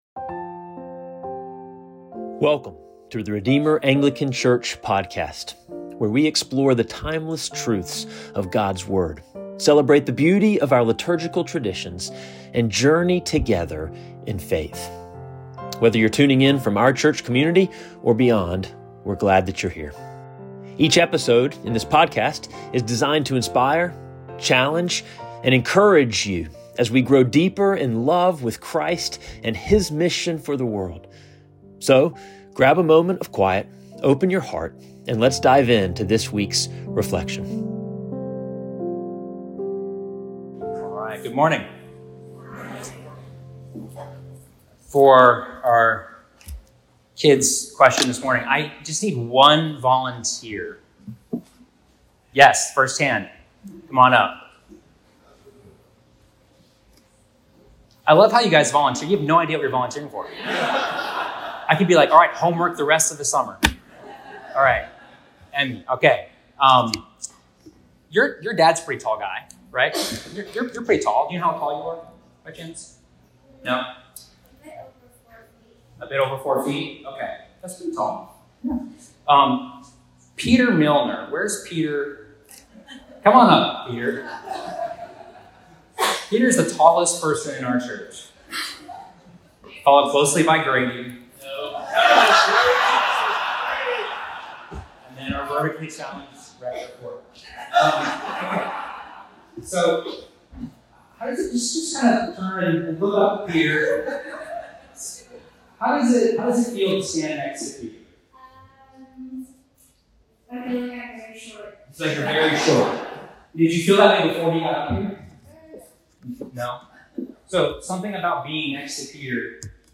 1 Sermon – A Message from Bishop Andudu